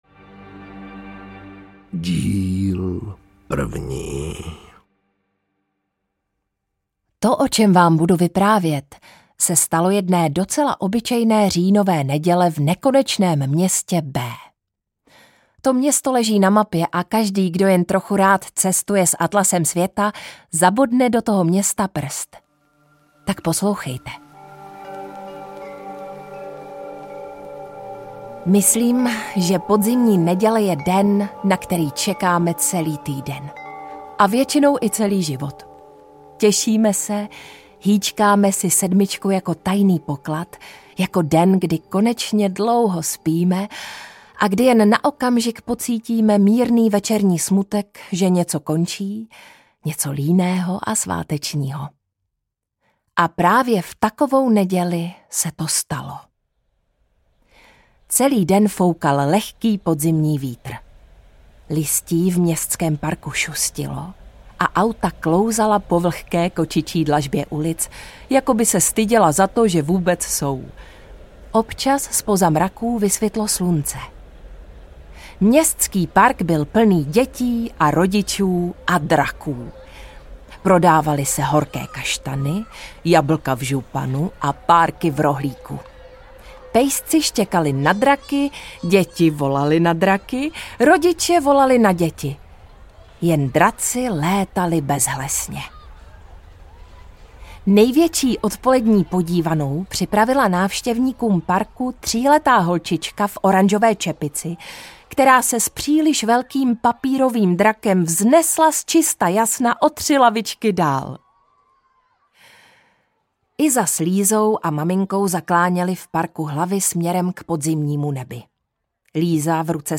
Pan Nikdo a bílá tma audiokniha
Ukázka z knihy